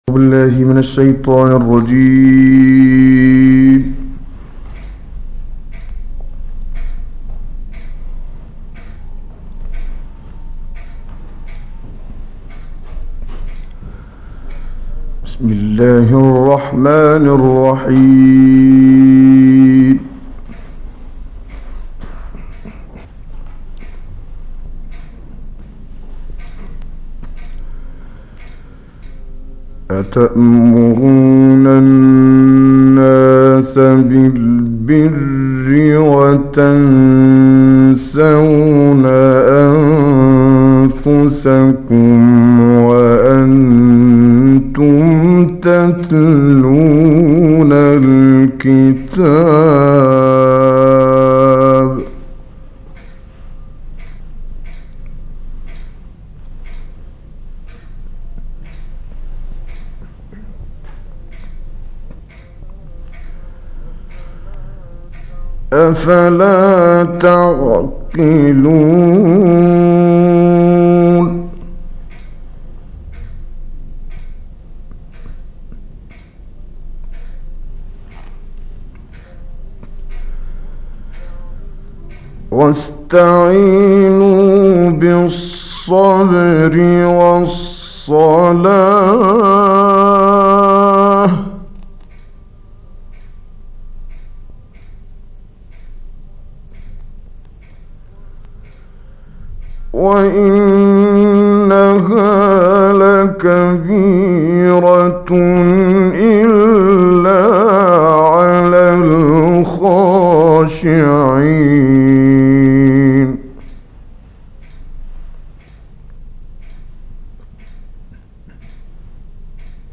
تلاوت استودیویی «کامل یوسف البهتیمی»
گروه شبکه اجتماعی: تلاوت استودیویی آیاتی از سوره بقره با صوت کامل یوسف البهتیمی را می‌شنوید.
به گزارش خبرگزاری بین المللی قرآن(ایکنا) تلاوت استودیویی آیات 44 تا 63 سوره بقره با صوت کامل یوسف البهتیمی، قاری برجسته مصری در کانال تلگرامی قرآنی تلحین منتشر شده است.